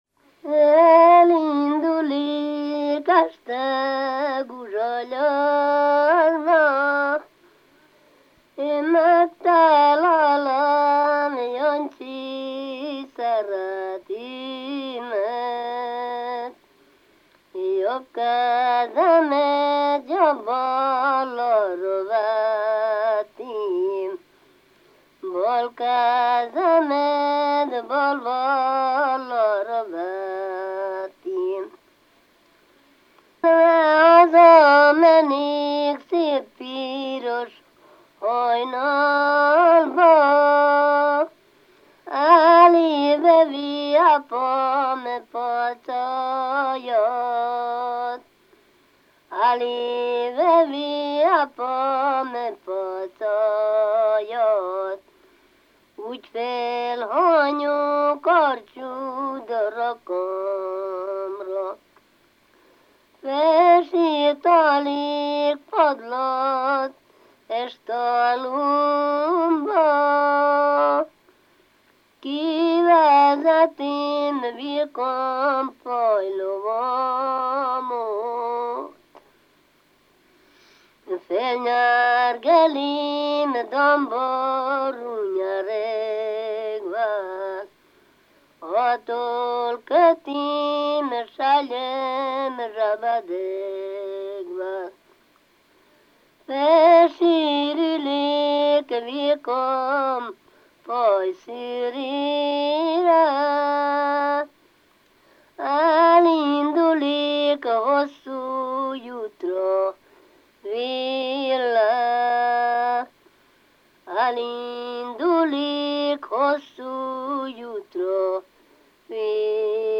ének
ballada
Klézse
Moldva (Moldva és Bukovina)